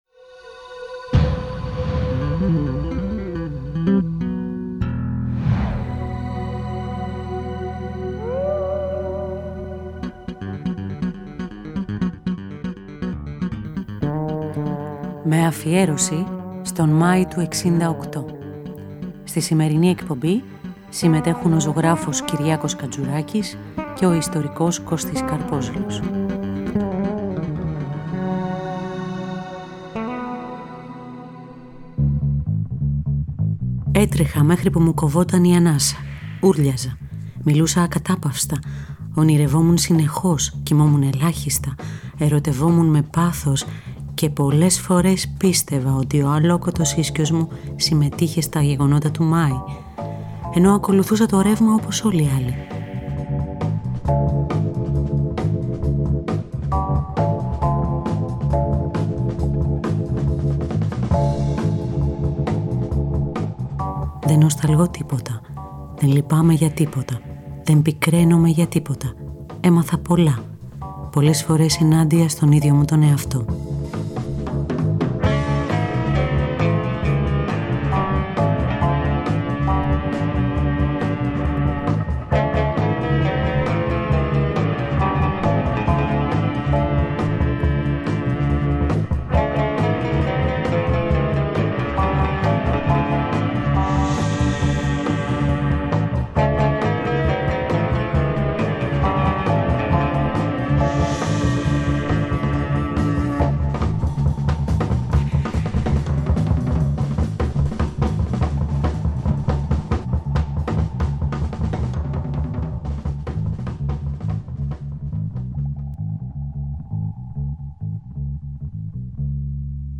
Ένα ραδιοφωνικό ντοκιμαντέρ για τον πιο θρυλικό Μάη της Ιστορίας μεαρχειακό υλικό, μαρτυρίες, ημερολογιακή αναφορά στα γεγονότα, μουσική, αναφορά στον πρωταγωνιστικό ρόλο του ραδιοφώνου στην παρισινή εξέγερση.